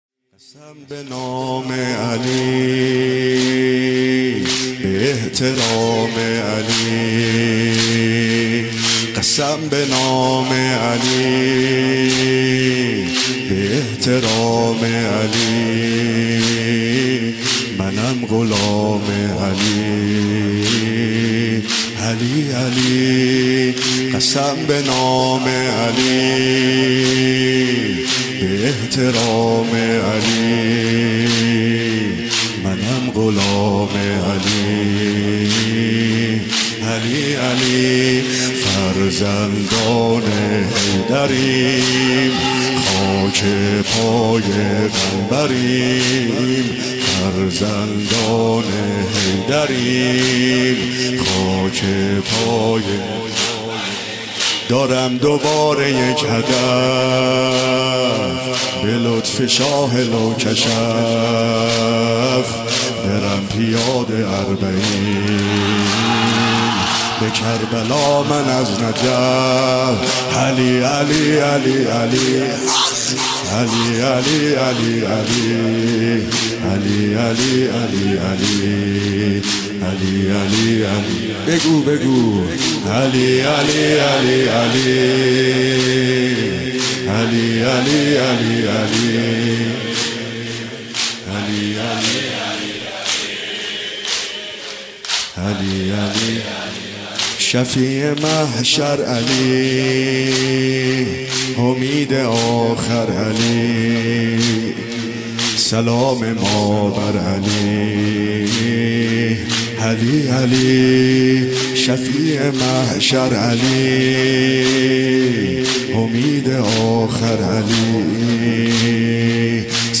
بشنوید/ مداحی «عبدالرضا هلالی» در شب قدر